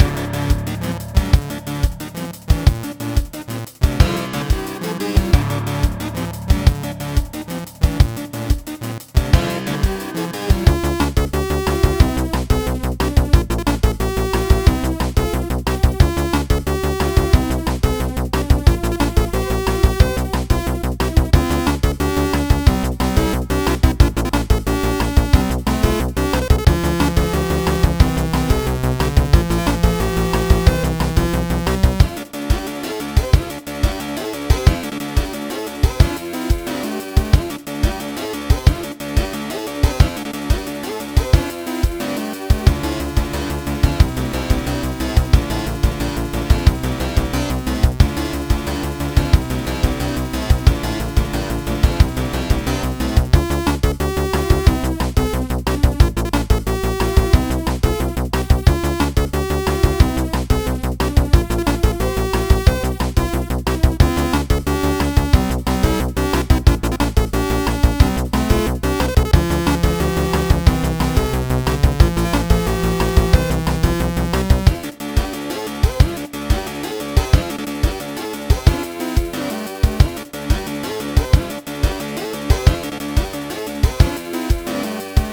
A small upbeat/racing theme